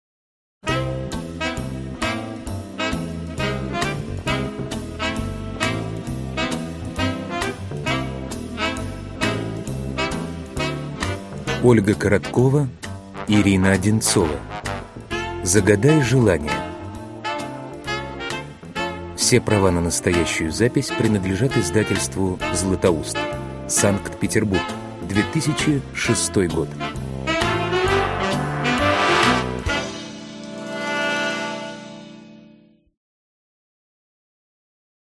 Аудиокнига Загадай желание.